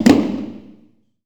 TC PERC 15.wav